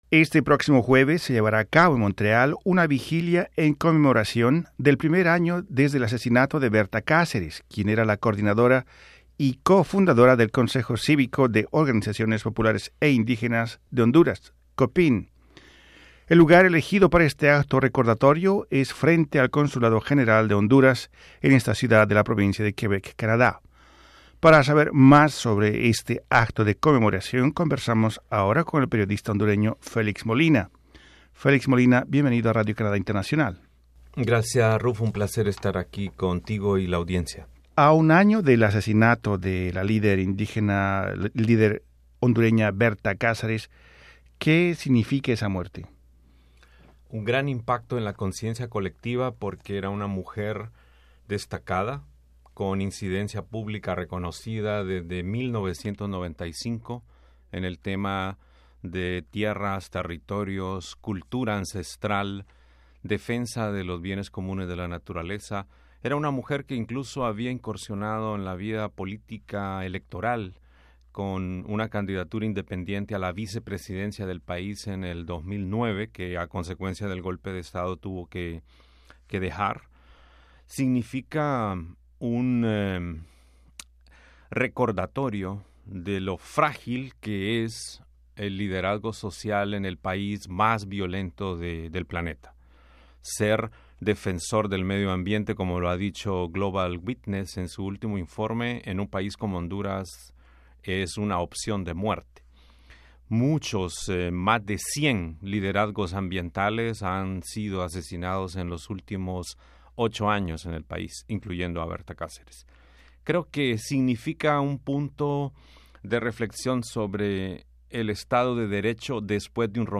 En conversación con Radio Canadá Internacional